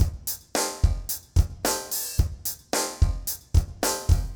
RemixedDrums_110BPM_35.wav